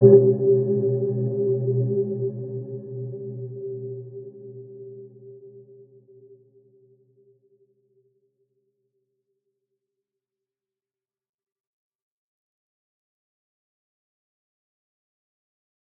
Dark-Soft-Impact-G4-mf.wav